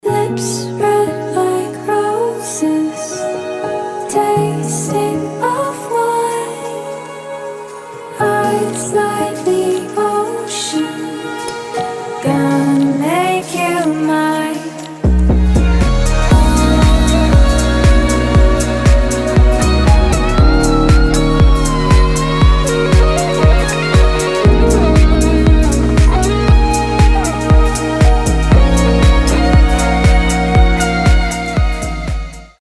• Качество: 320, Stereo
гитара
deep house
мелодичные